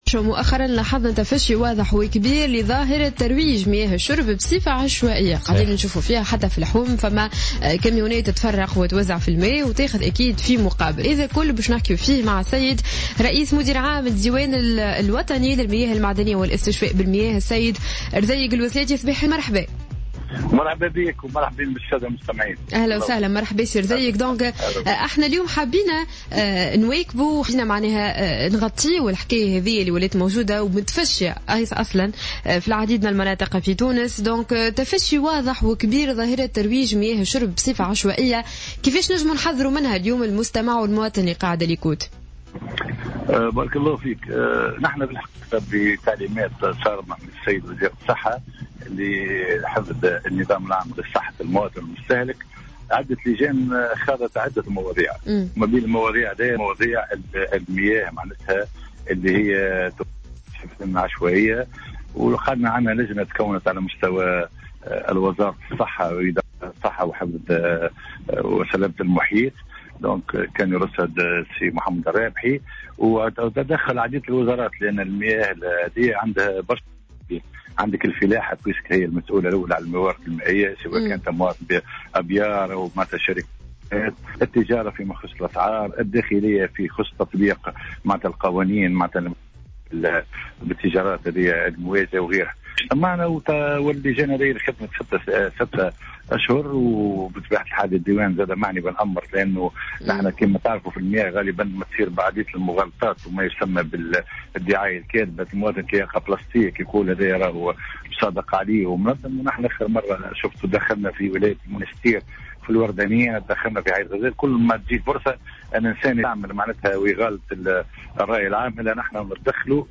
أكد رئيس مدير عام الديوان الوطني للمياه المعدنية والاستشفاء بالمياه رزيق الوسلاتي في مداخلة له في برنامج صباح الورد على جوهرة "اف ام" اليوم الخميس 10 سبتمبر 2015 أن هناك لجان خاصة تكونت لمناقشة موضوع ترويج مياه الشرب بصفة عشوائية من بينها لجنة تشكلت على مستوى وزارة الصحة للنظر في هذه المسألة وإيجاد حلول عاجلة للقضاء عليها.